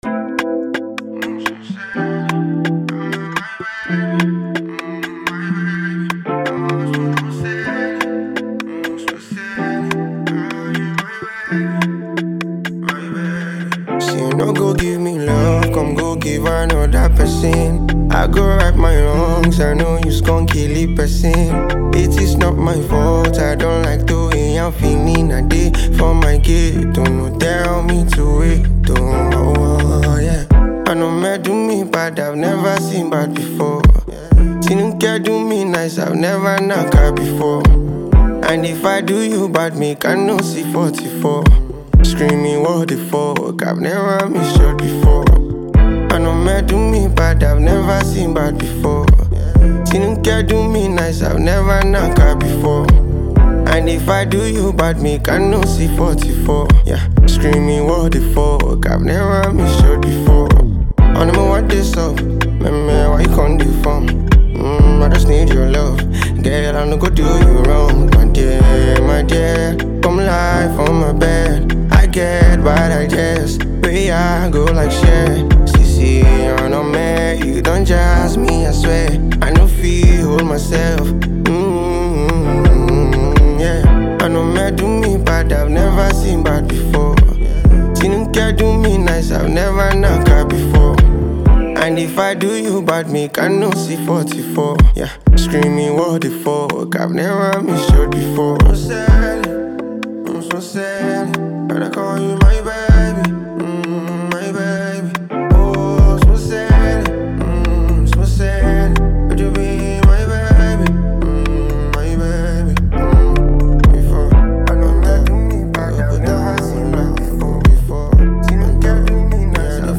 features an infectious beat and deeply moving lyrics